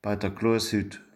hochdeutsch Gehlbergersch
Bei der Glashütte **  Bei dr Gloashütt